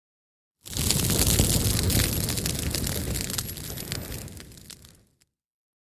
FIRE BRUSH FIRE: EXT: Bursts of roaring, crackly fire, gas vent.. Fire Burn.